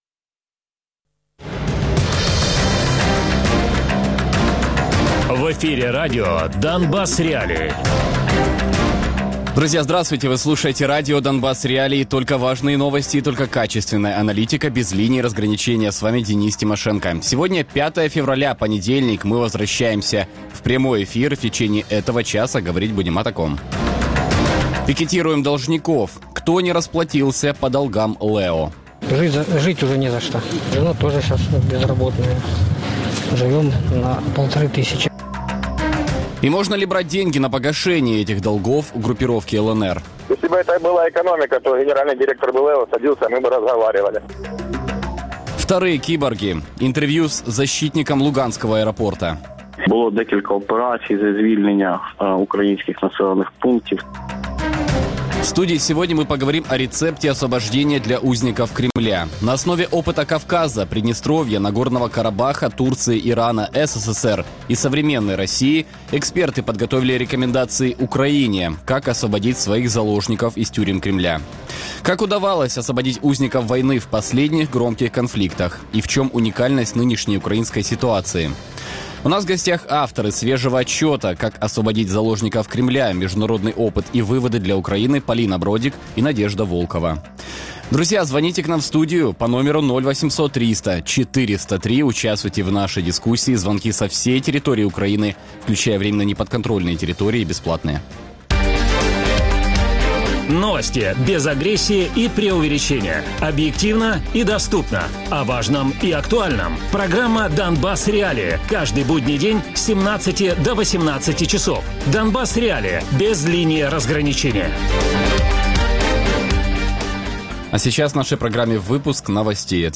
експерт Української Гельсінської спілки з прав людини Радіопрограма «Донбас.Реалії» - у будні з 17:00 до 18:00. Без агресії і перебільшення. 60 хвилин про найважливіше для Донецької і Луганської областей.